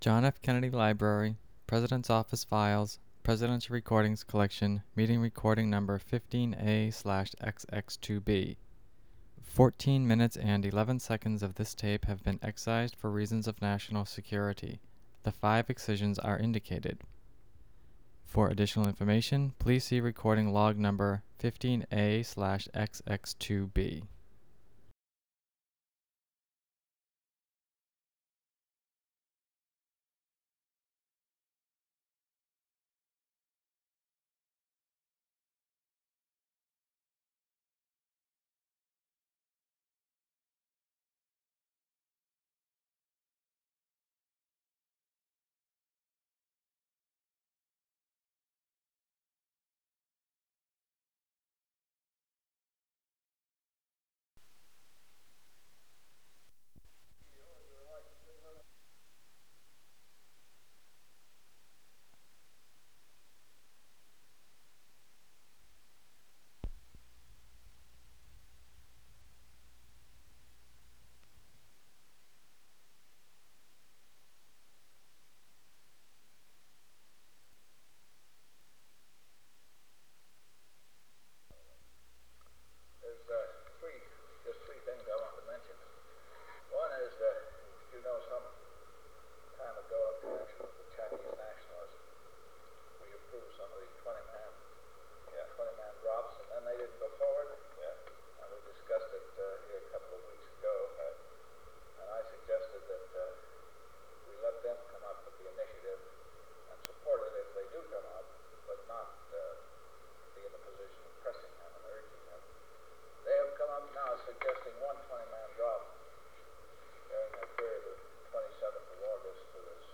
Meeting on Intelligence Matters
Secret White House Tapes | John F. Kennedy Presidency Meeting on Intelligence Matters Rewind 10 seconds Play/Pause Fast-forward 10 seconds 0:00 Download audio Previous Meetings: Tape 121/A57.